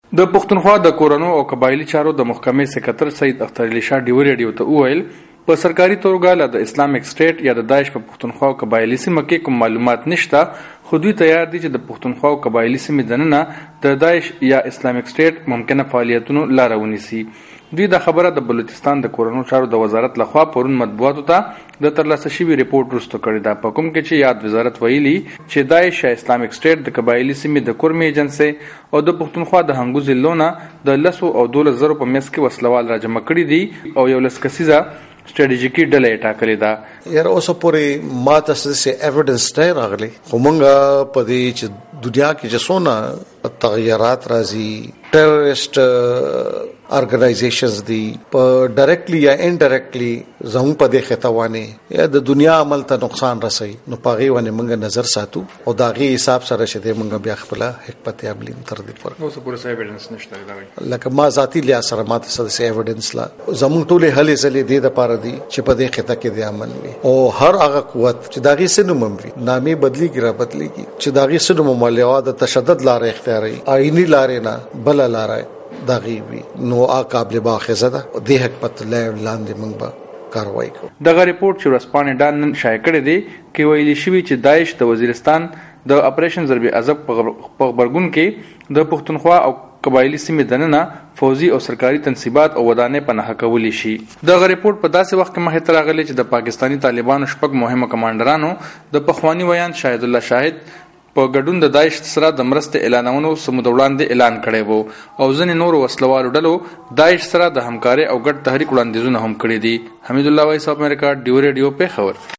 دا خبره خیبر پښتونخوا د کورنو چارو او د قبائیلي سېمو دپاره صوبائي سیکریټري سید اختر علي شاه وائس اف امریکا ډیوه ریډیو سره یوه مرکه کې کړې ده.